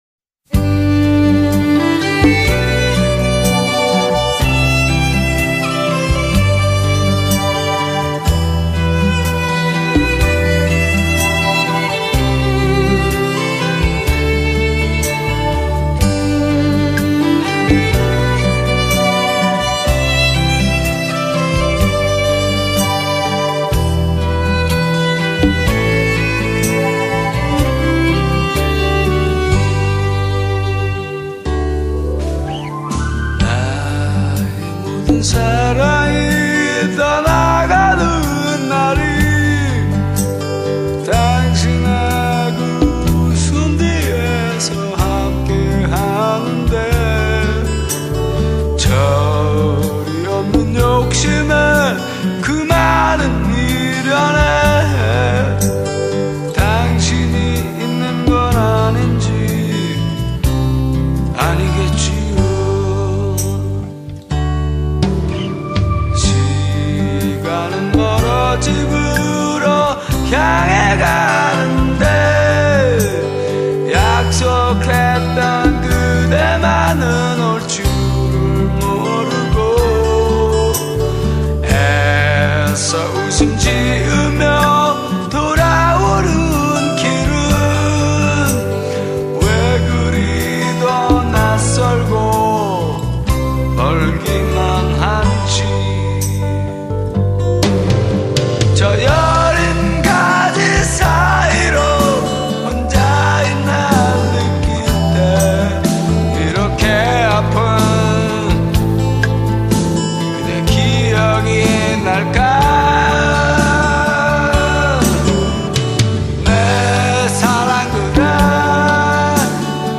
尽管声线沙哑，他用尽全身的力气高歌，尽管感受到无尽的苦楚，却丝毫不减他对歌唱的热情。